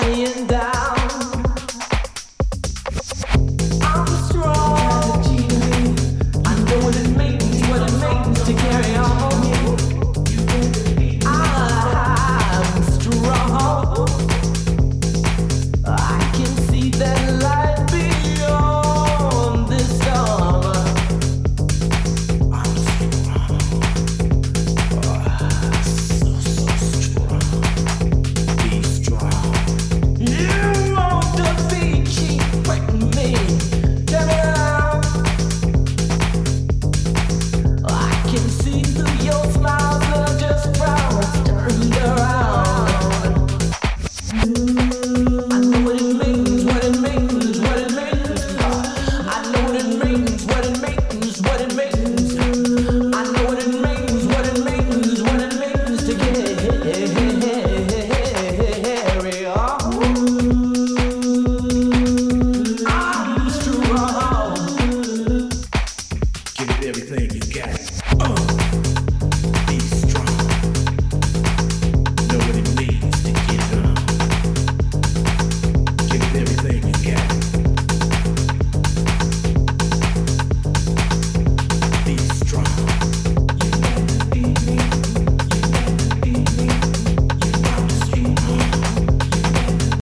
undated and tough but still has attitude.
Chicago house
Acid house
Deep house